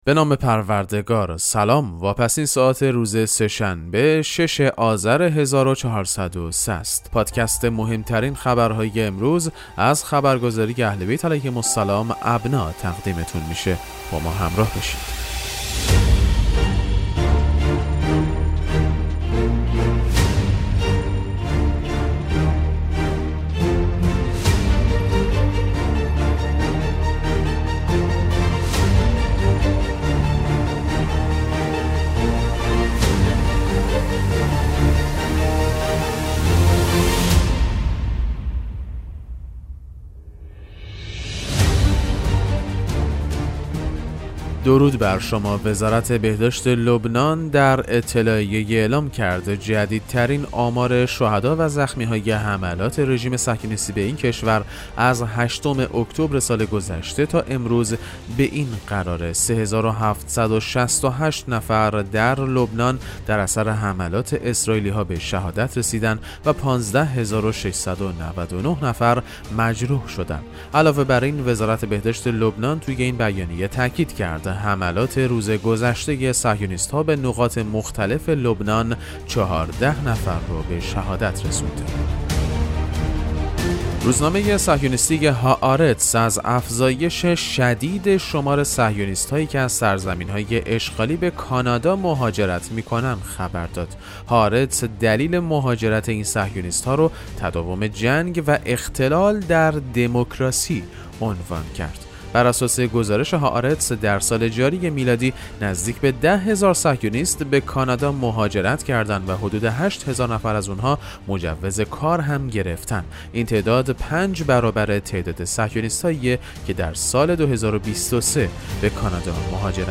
پادکست مهم‌ترین اخبار ابنا فارسی ــ 6 آذر 1403